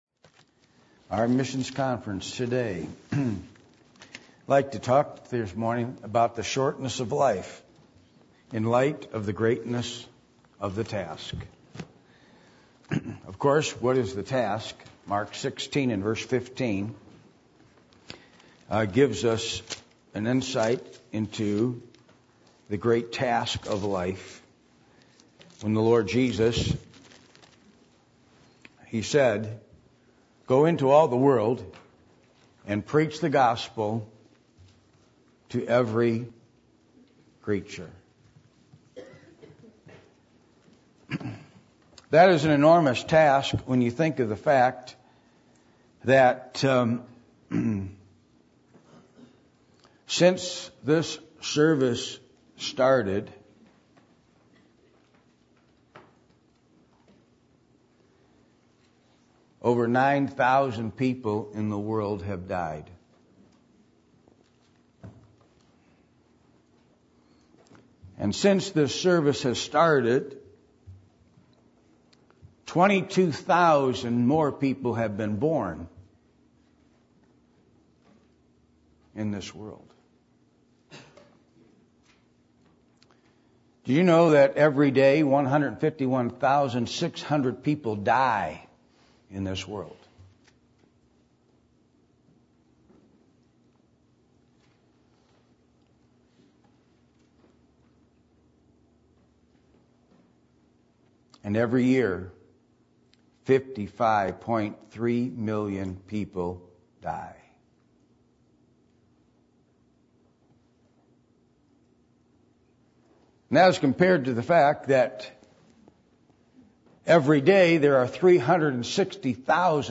Passage: Isaiah 40:1-10 Service Type: Sunday Morning